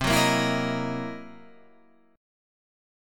C7#9b5 chord